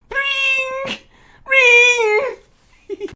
男子说" Ring Ring"像电话一样
描述：一个男人疯狂地说'戒指戒指！'
标签： 有趣 疯狂 戒指 手机